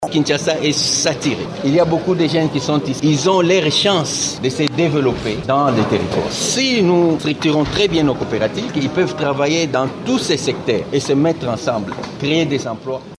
Ecoutez sa réaction du ministre: